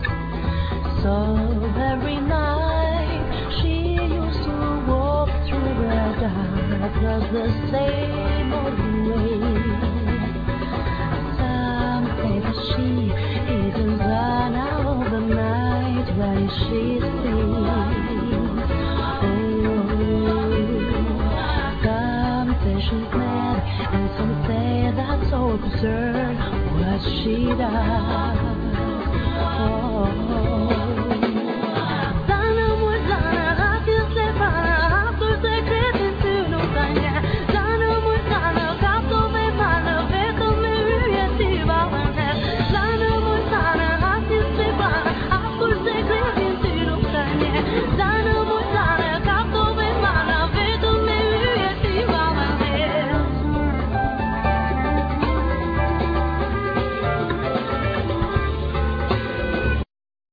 Vocals
Piano
Drums
Double bass, E-bass
Bakllama, Lute, Percussions
Accordion
Solo Clarinet